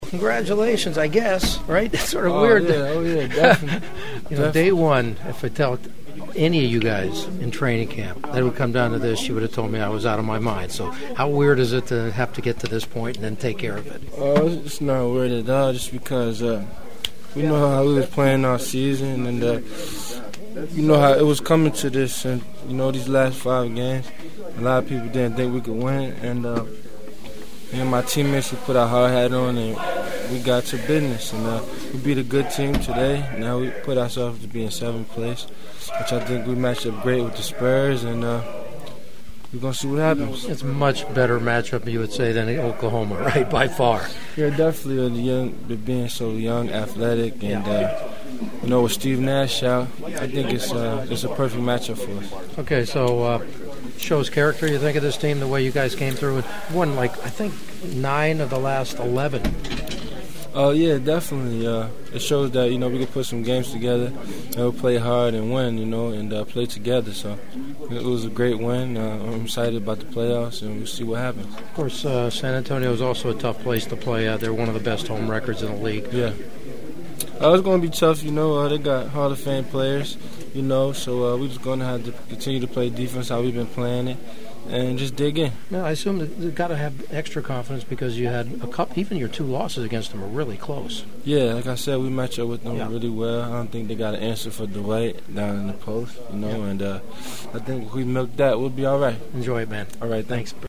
I had some great postgame locker room chats that unfortunately I won’t be able to share with you since they were messed up by a microphone malfunction.